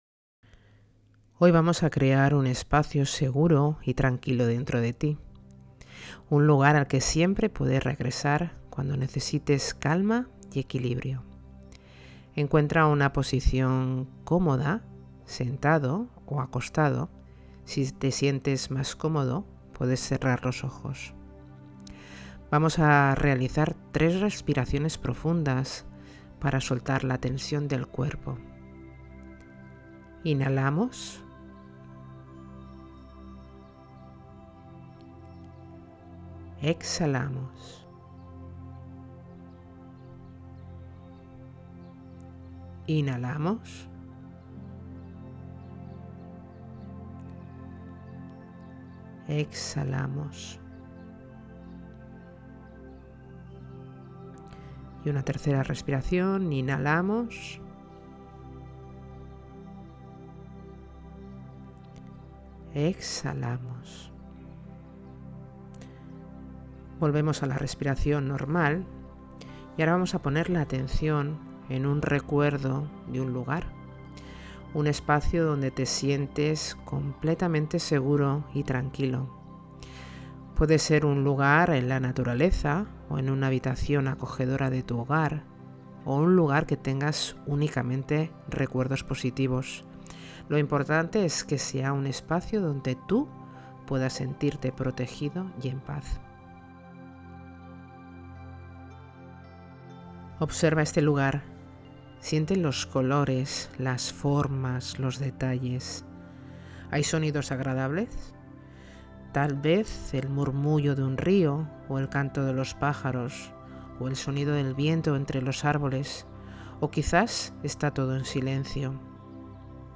Meditación – Tu Lugar Seguro
Esta meditación es la realizada en el encuentro del 13.12.2024 por lo que si aún no viste el directo, es importante que veas antes el directo para darle más contexto a esta meditación.